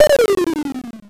06-RayGun.wav